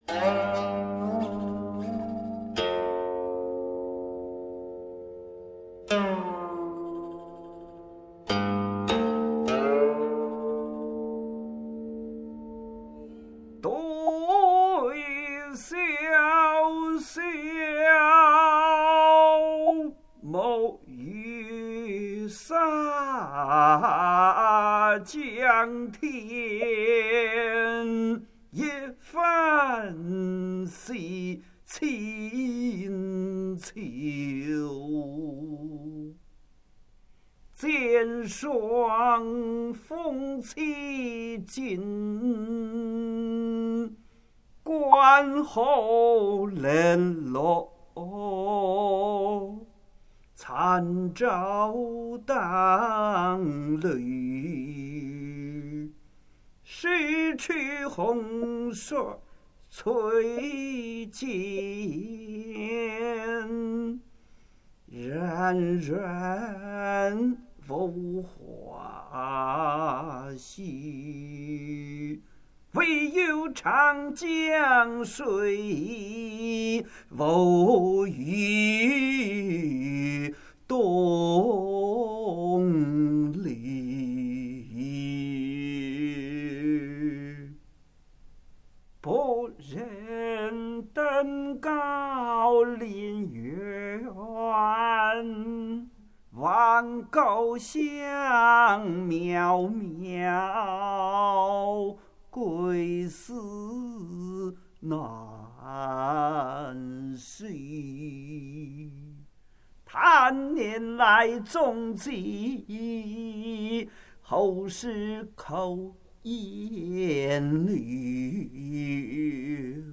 吟唱